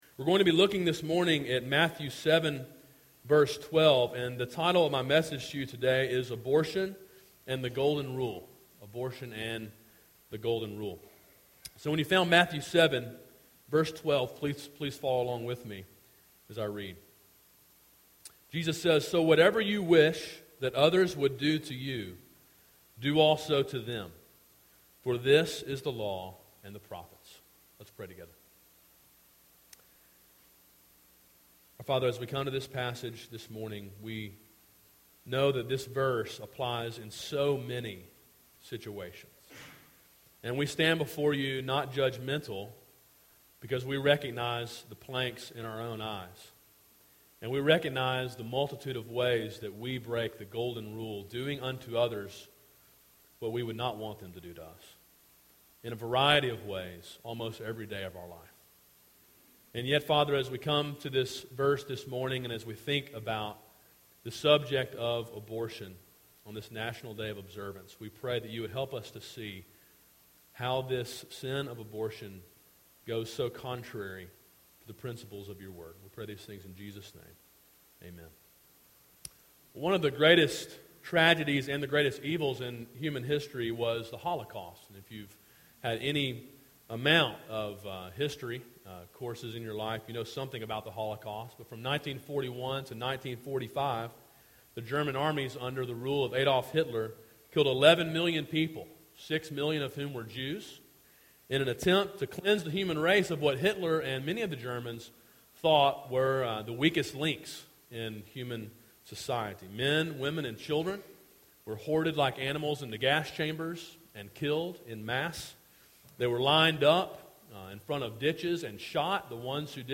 A sermon preached on National Sanctity of Human Life Sunday and in conjunction with a series titled Sermon on the Mount: Gospel Obedience.